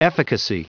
Prononciation du mot efficacy en anglais (fichier audio)
Prononciation du mot : efficacy